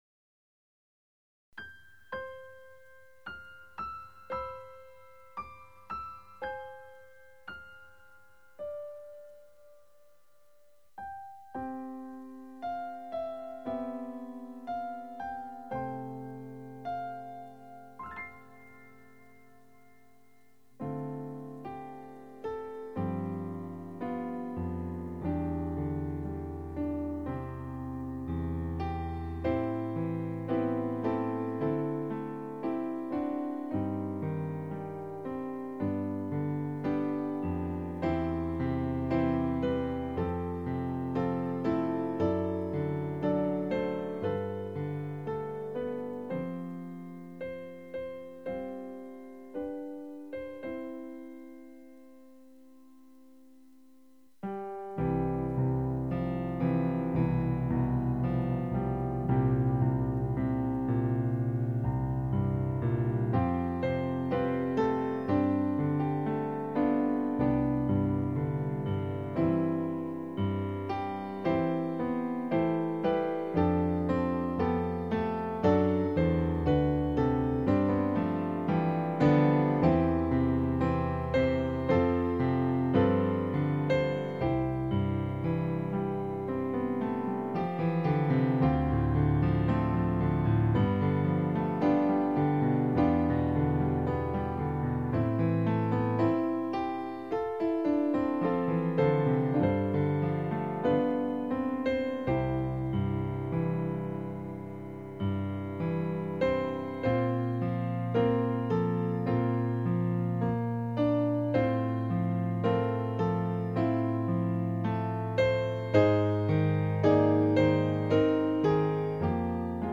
Piano Solo
Voicing/Instrumentation: Piano Solo We also have other 52 arrangements of " It is Well with My Soul ".